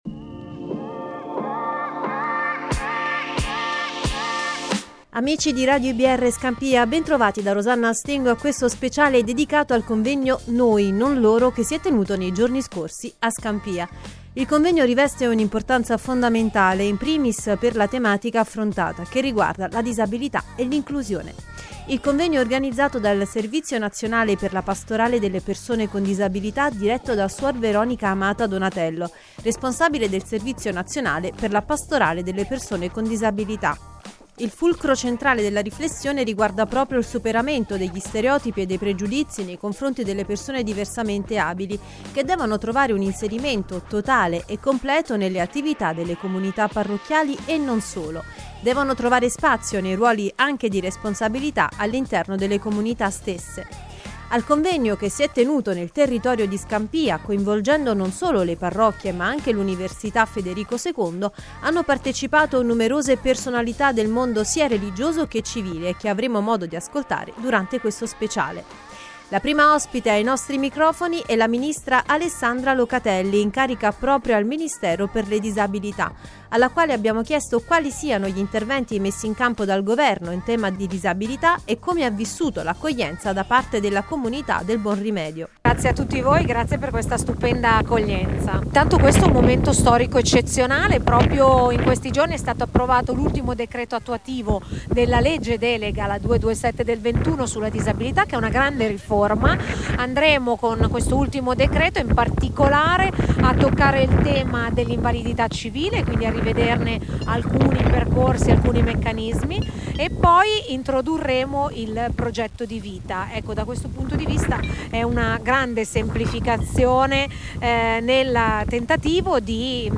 Per Radio iBR Scampia abbiamo intervistato alcuni degli intervenuti, di seguito l’audio delle interviste e una galleria dell’evento.